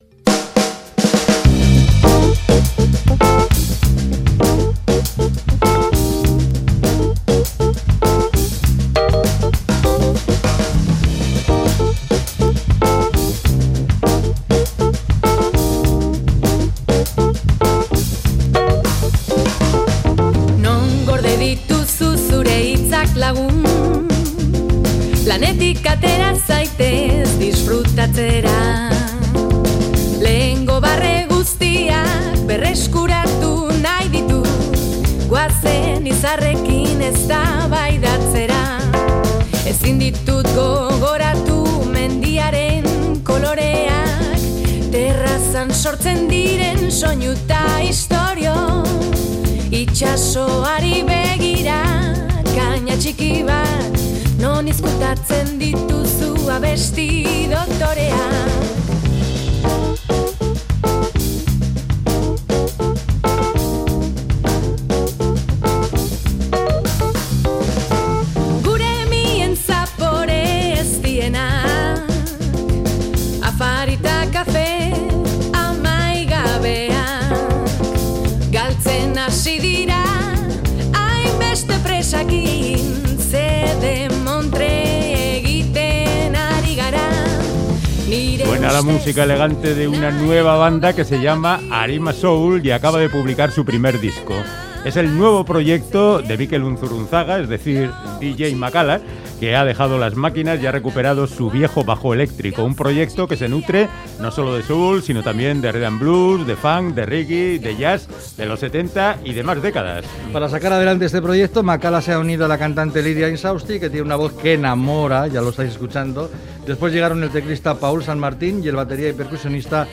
Charlamos